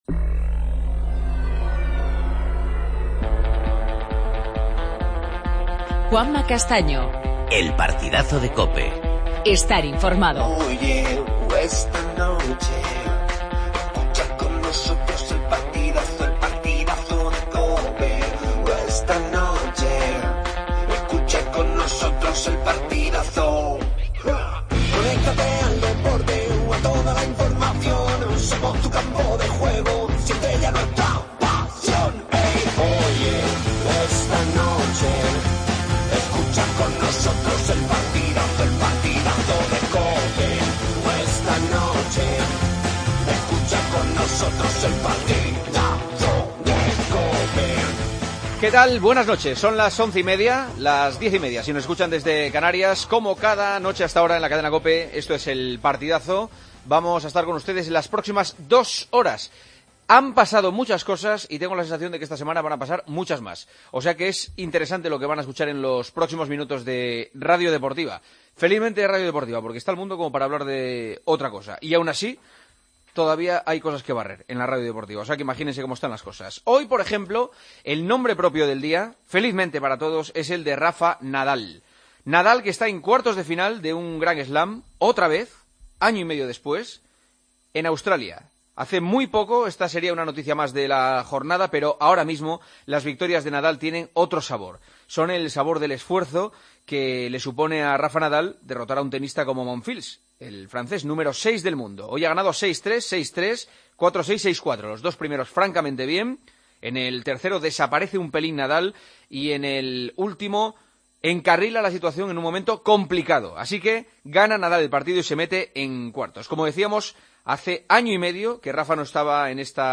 Entrevista a Toni Nadal, entrenador y tío de Rafa Nadal.